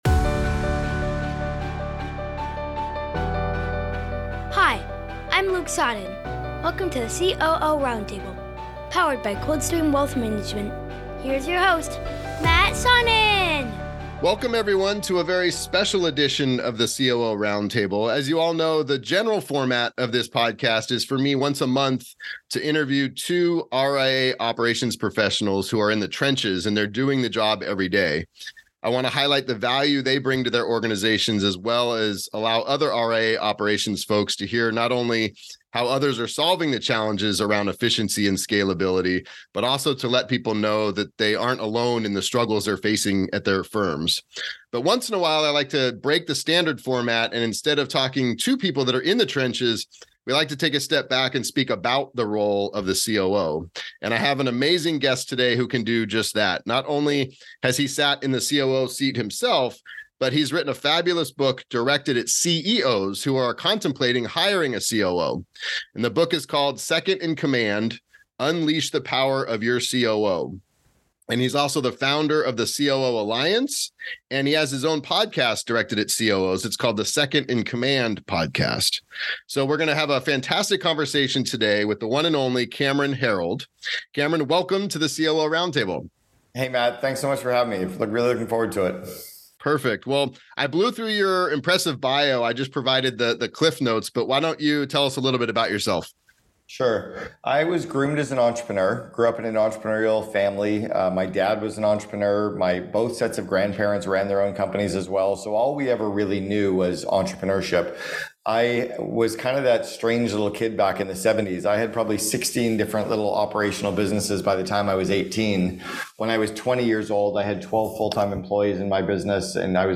For this episode, we step away from our normal format of speaking with operations professionals in the trenches, to speaking with an author and entrepreneur about the role of the COO.